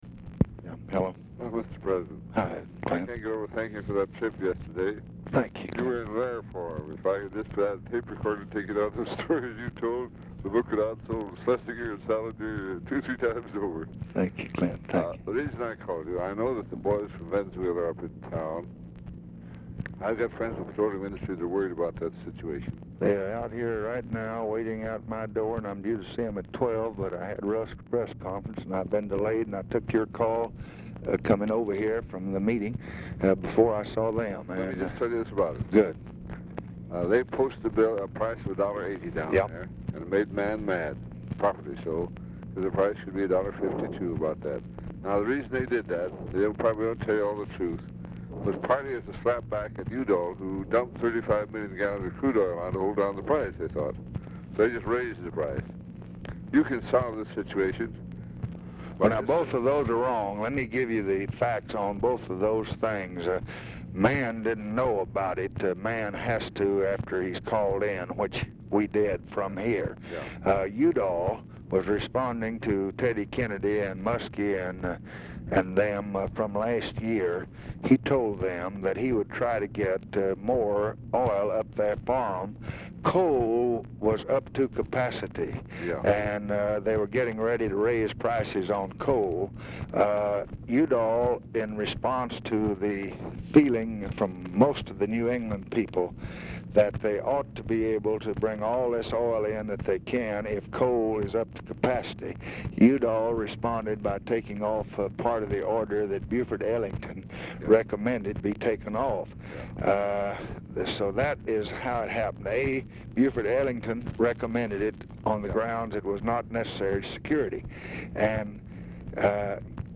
Telephone conversation # 9525, sound recording, LBJ and CLINTON ANDERSON, 1/21/1966, 12:40PM | Discover LBJ
Format Dictation belt
Location Of Speaker 1 Oval Office or unknown location